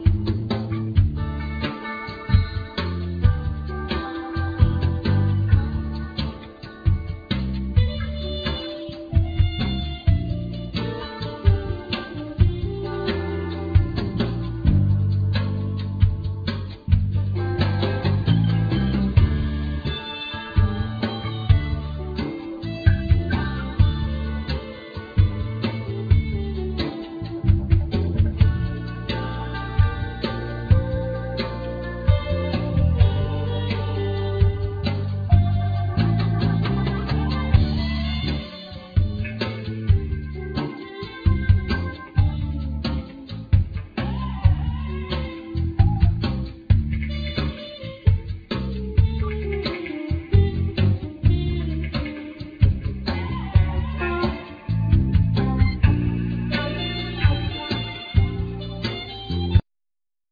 Trumpet
Turntables
Drums
Bass
Fender piano
Synthsizer, Hammond organ
African percussions
Guitar